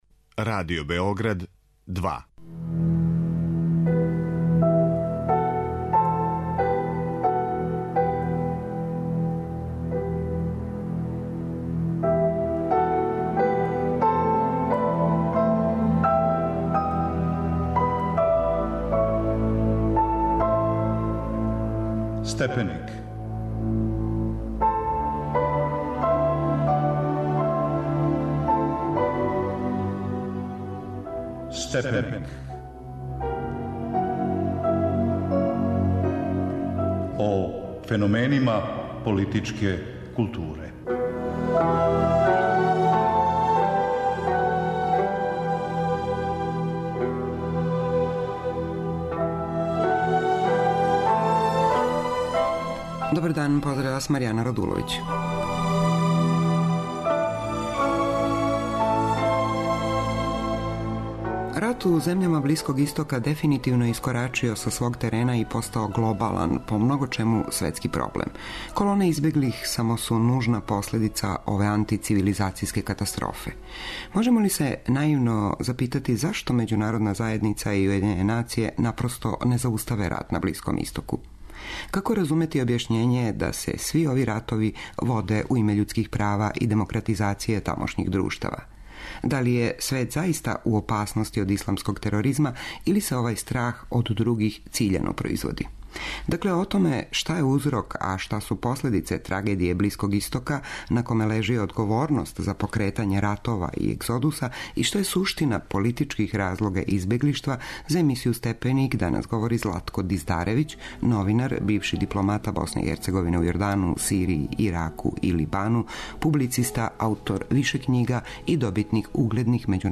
Дакле, о томе шта је узрок а шта последица трагедије на Блиском истоку, на коме лежи одговорност за покретање ратова и егзодуса, шта је суштина политичких разлога избеглиштву и, на крају, шта је у свему томе одржавање илузија а шта реалполитика, у емисији Степеник разговарамо са Златком Диздаревићем , новинарем, бившим дипломатом БиХ у Јордану, Сирији, Ираку и Либану, публицистом и добитником низа угледних међународних новинарских признања.